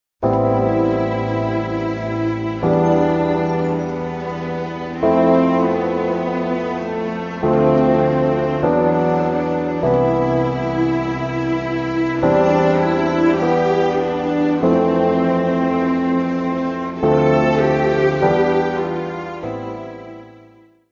Music Category/Genre:  New Musical Tendencies